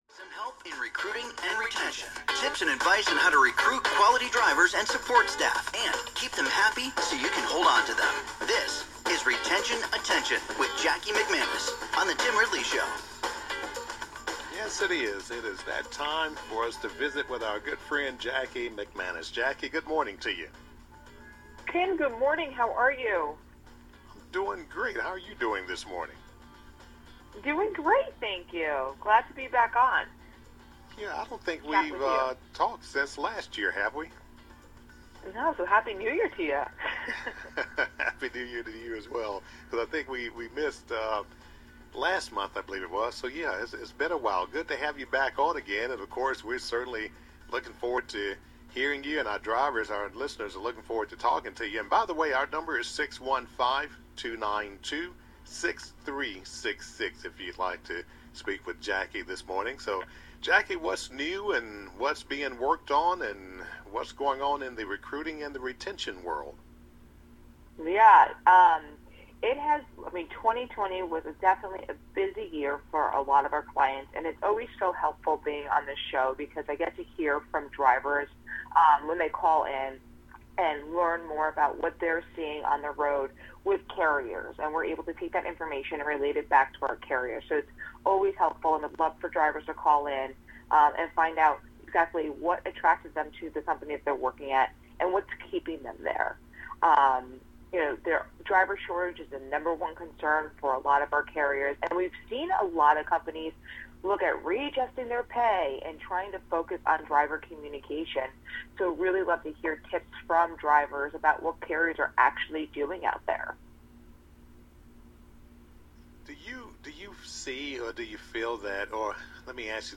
Several drivers call in to share their thoughts on carrier communication and where they go to look for new jobs.